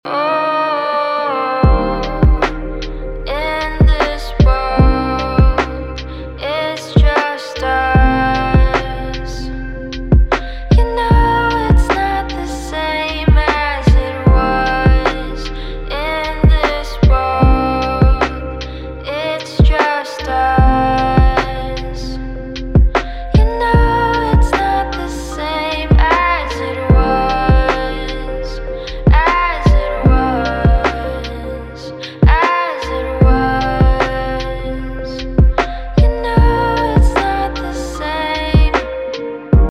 Жанр: Инди